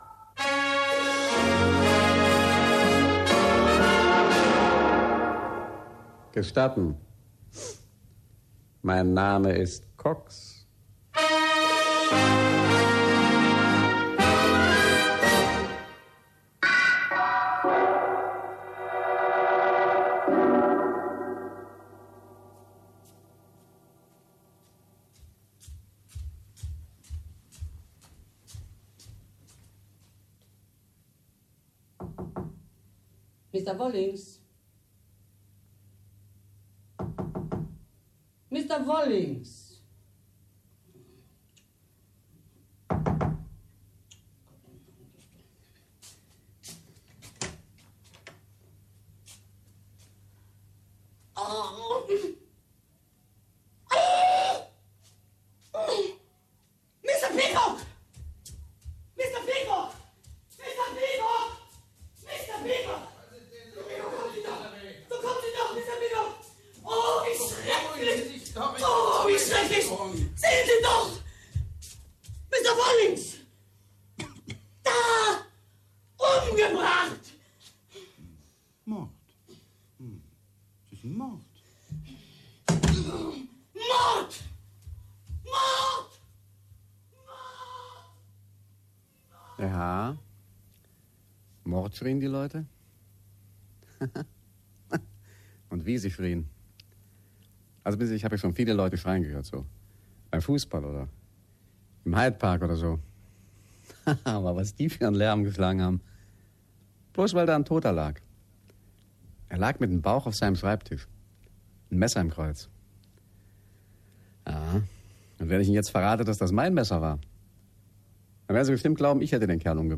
Cox hoorspelen in Duitsland
– 8 delen, met Carl-Heinz Schroth als Paul Cox
De rol van Mr. Peacock wordt in dit verhaal door twee acteurs gespeeld.